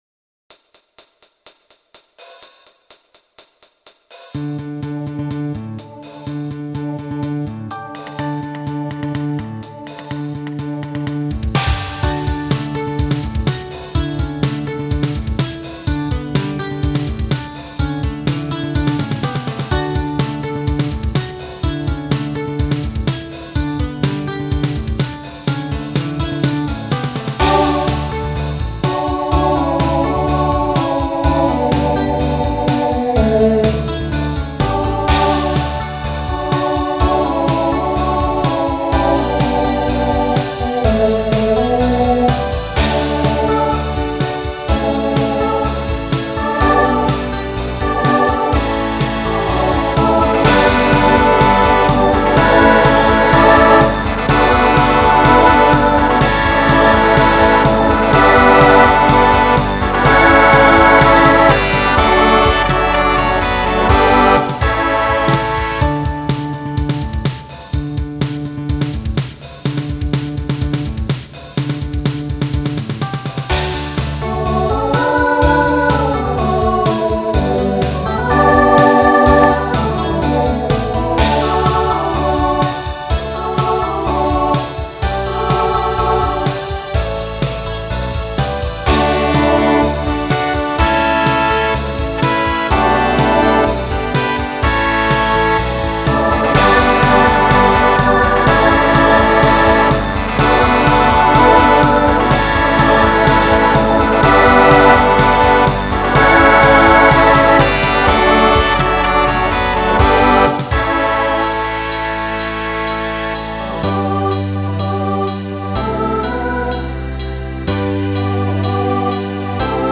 Voicing SATB Instrumental combo Genre Rock
2000s Show Function Mid-tempo